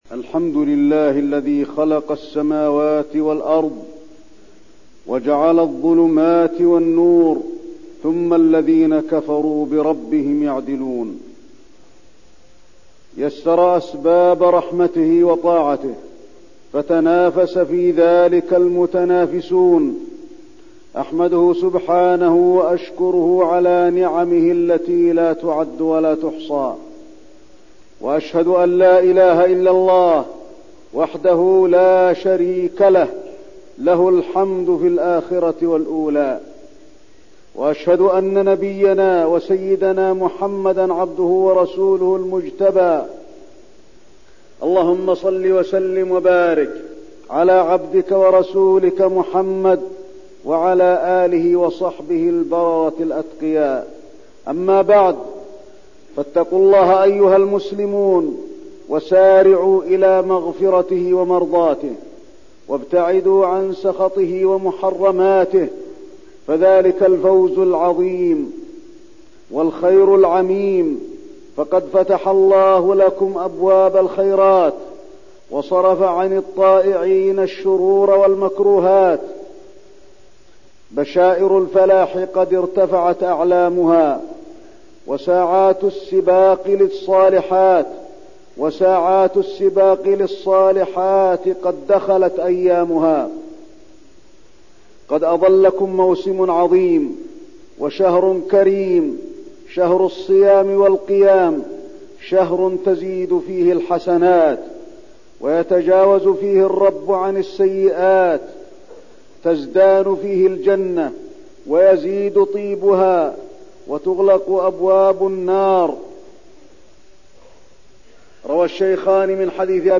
تاريخ النشر ٢٥ شعبان ١٤١٢ هـ المكان: المسجد النبوي الشيخ: فضيلة الشيخ د. علي بن عبدالرحمن الحذيفي فضيلة الشيخ د. علي بن عبدالرحمن الحذيفي استقبال شهر رمضان